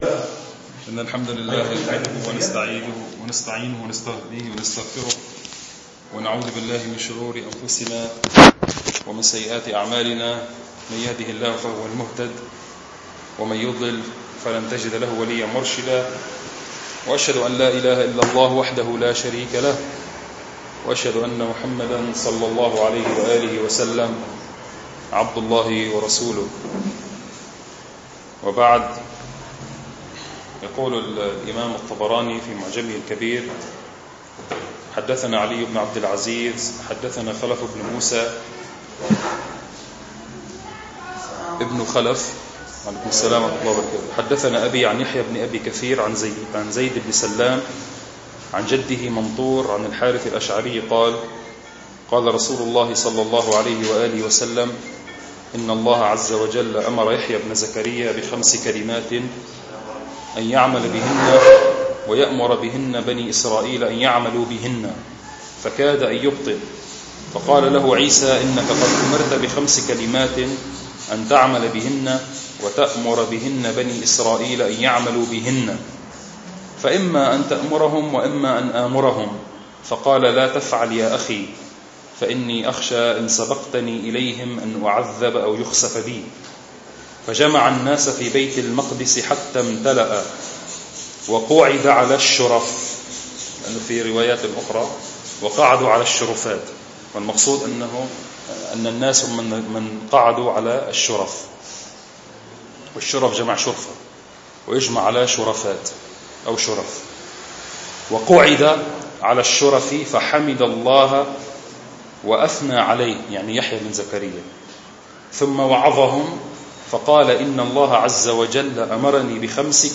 المكان : مبنى جماعة عباد الرحمن